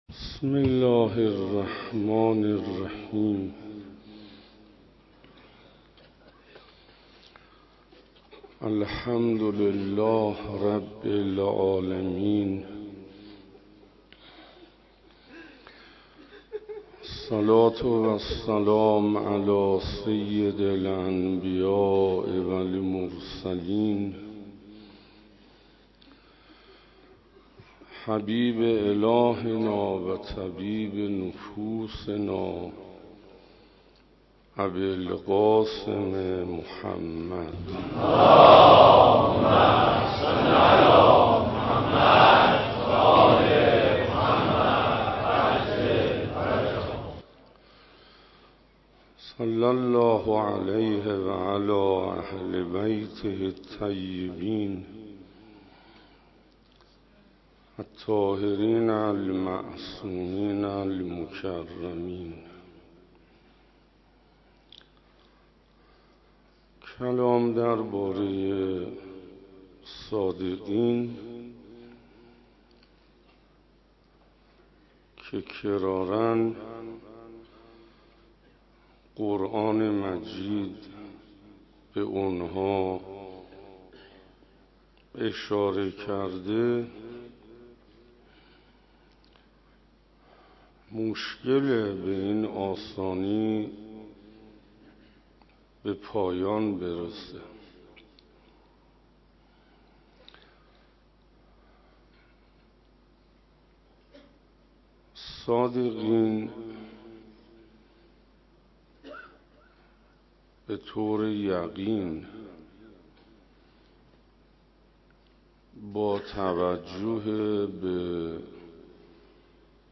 روزهفتم محرم1439 - حسینیه هدایت